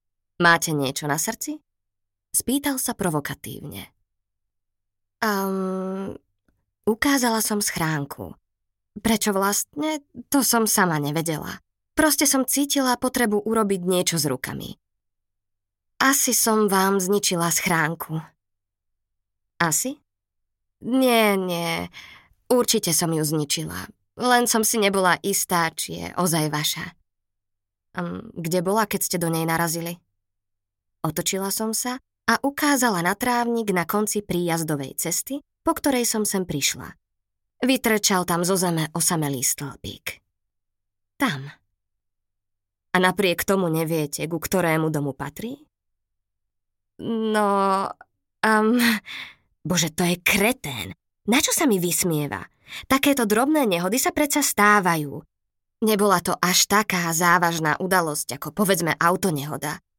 Hörbuch
MP3 Audiobook,